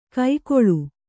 ♪ kai koḷu